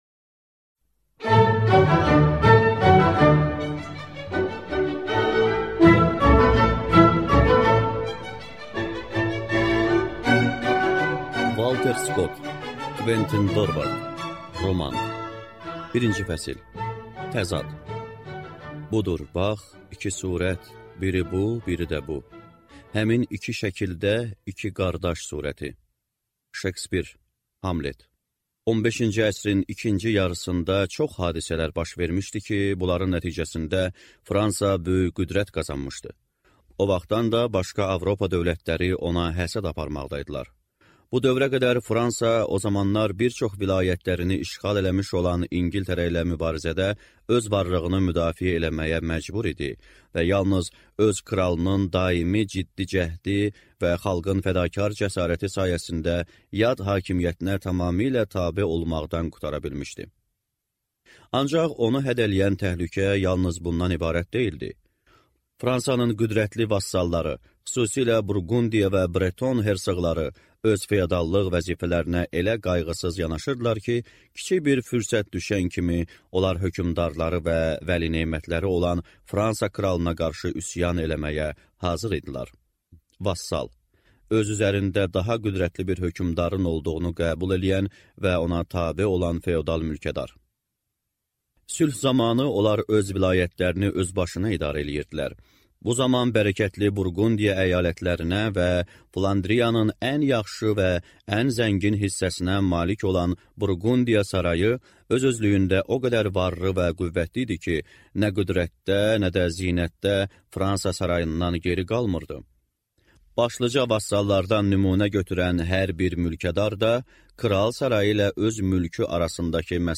Аудиокнига Kventin Dorvard | Библиотека аудиокниг